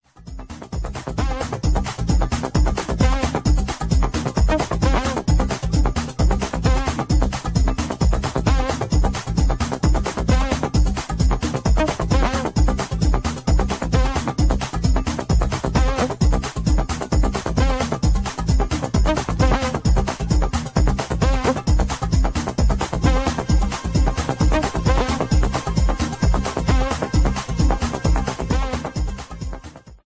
Detroit techno classic